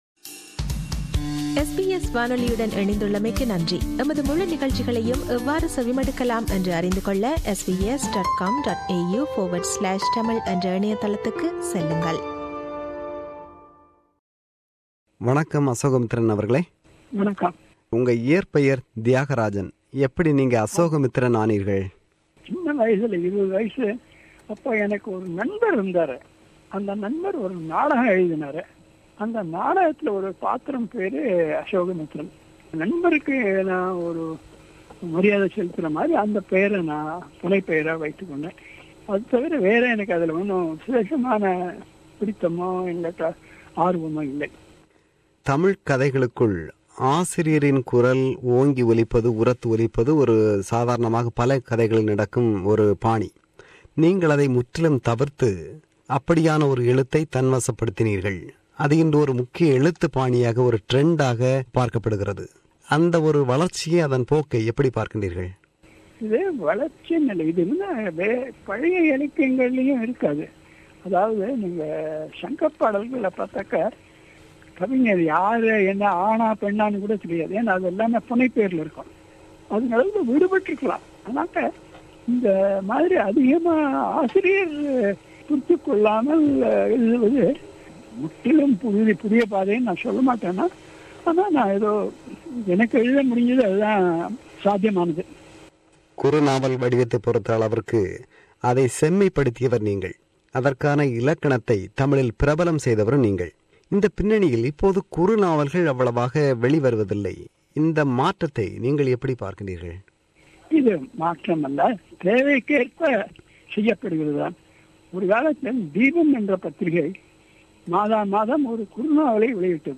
Its an abridged version of his interview.